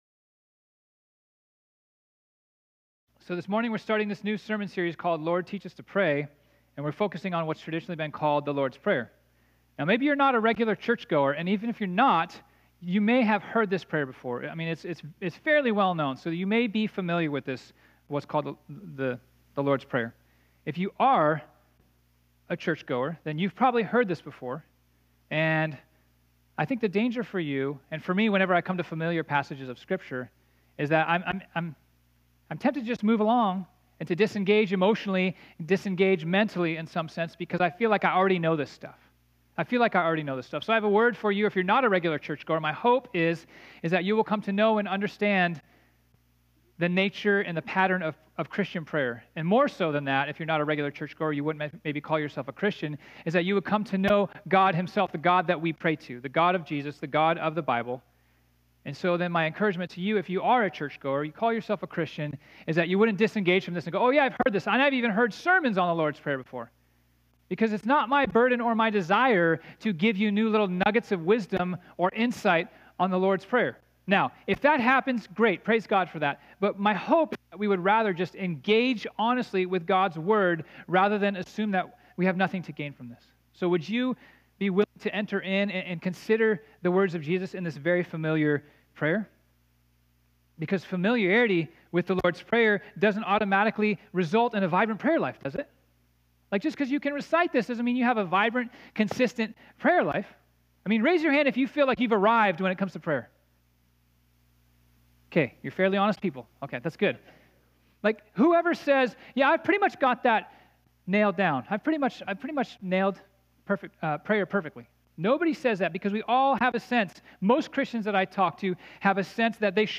This sermon was originally preached on Sunday, June 23, 2019.